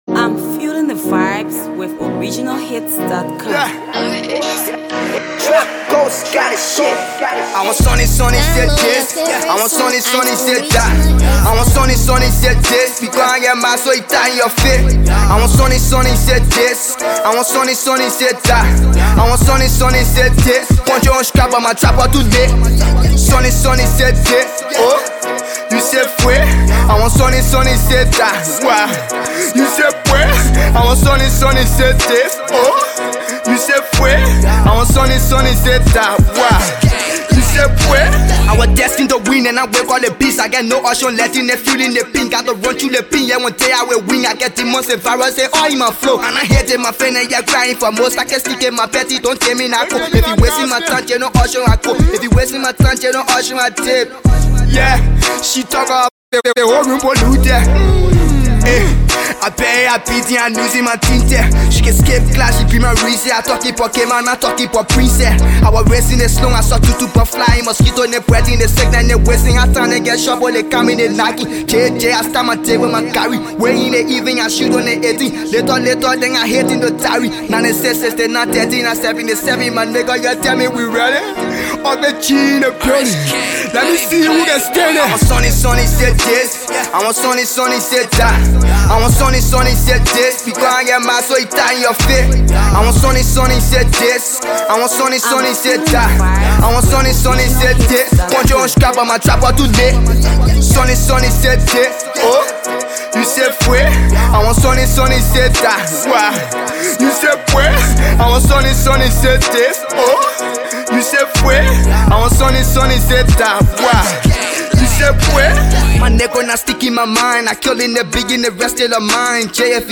Liberian uprising trap artist